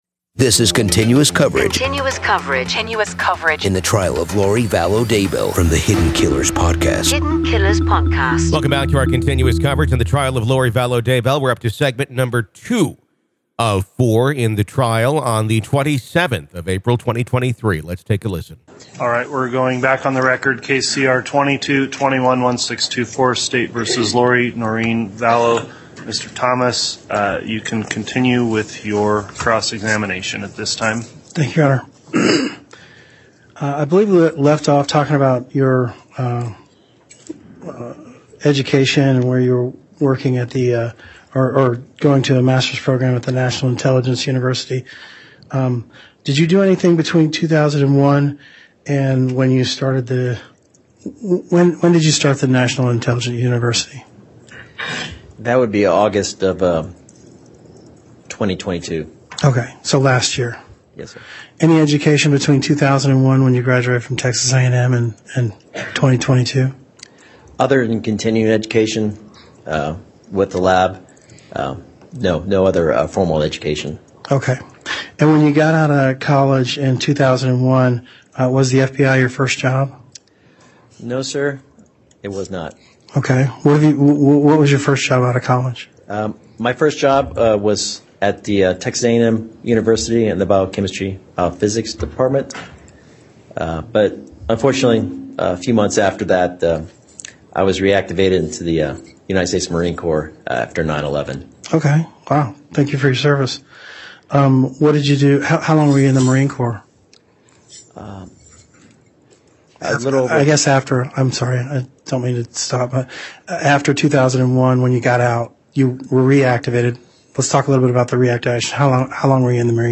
The Trial Of Lori Vallow Daybell Day 12 Part 2| Raw Courtroom Audio